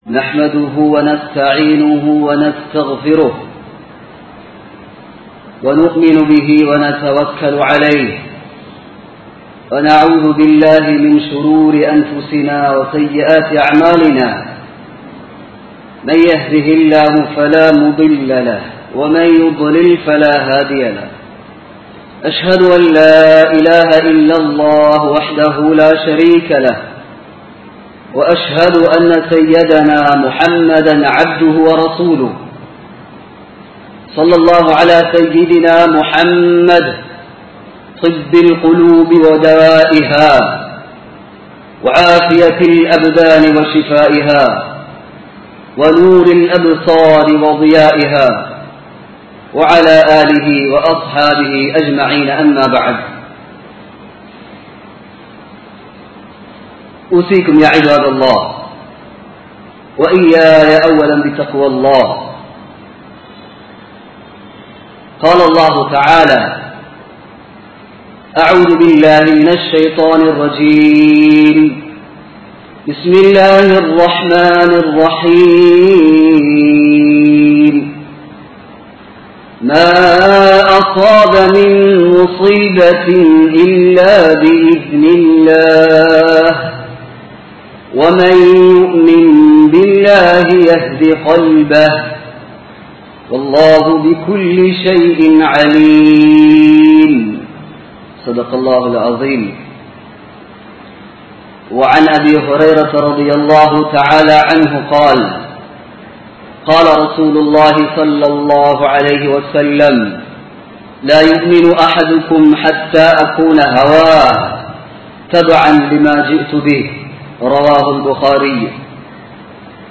மறுமை வாழ்க்கை | Audio Bayans | All Ceylon Muslim Youth Community | Addalaichenai
Colombo 11, Samman Kottu Jumua Masjith (Red Masjith) 2022-01-28 Tamil Download